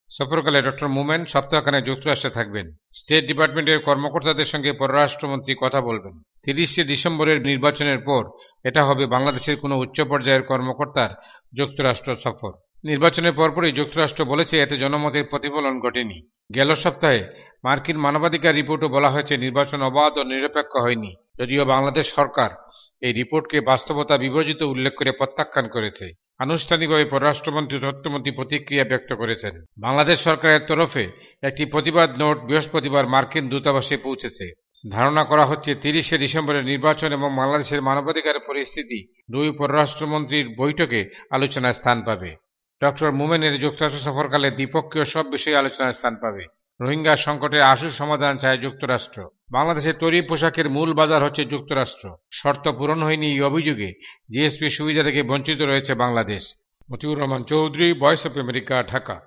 ঢাকা থেকে